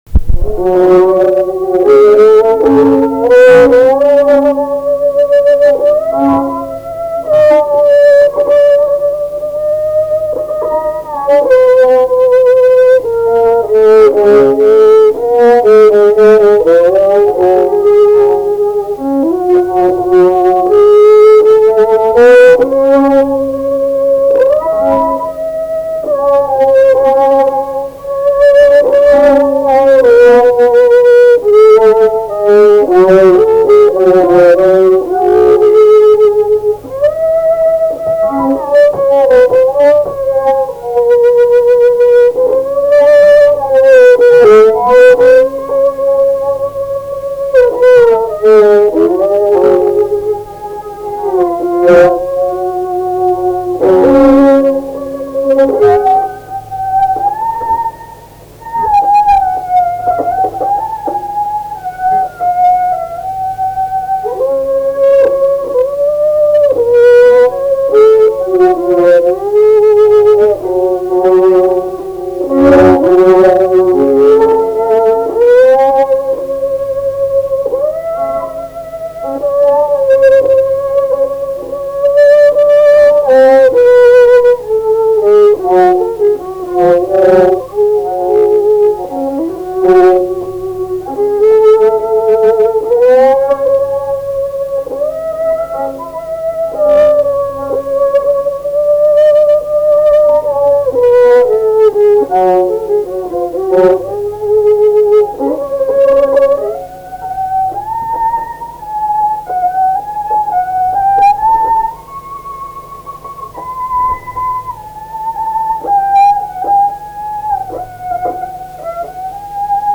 Anglų valsas
šokis